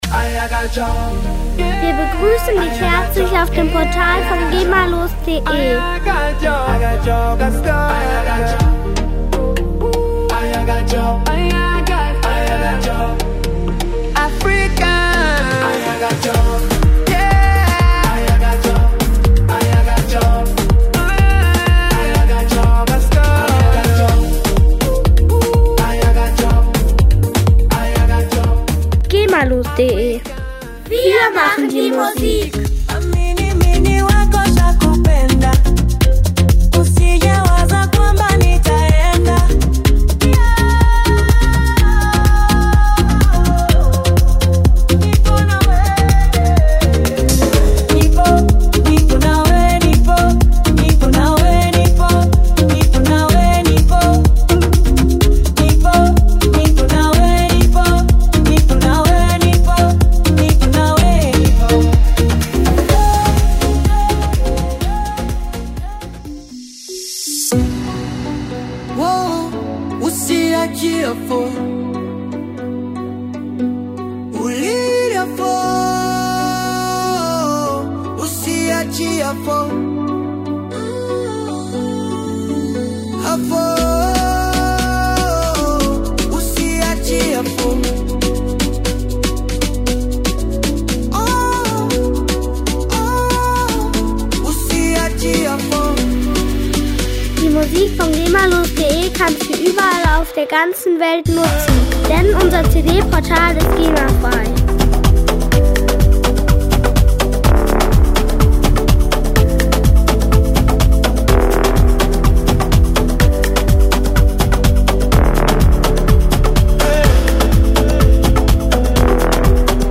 Musikstil: Deep African House
Hörprobe [4.878 KB]